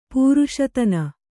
♪ pūruṣatana